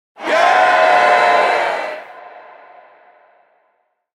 Concert Audience Cheering Sound Effect
Yeah-crowd-sound-effect.mp3